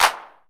SnootGame/game/audio/effects/clap.ogg at ca7406d0d90448cc0bc4b0aef1be2c50ad69901e
clap.ogg